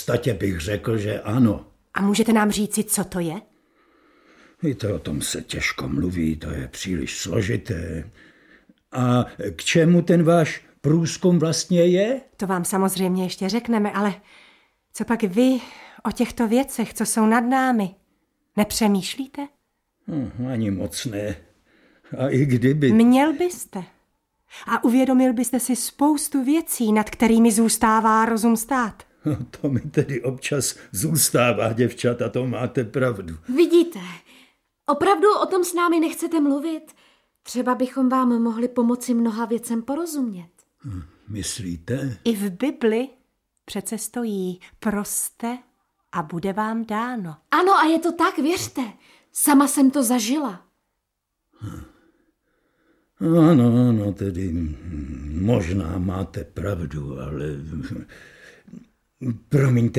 Audiobook
Read: Taťjana Medvecká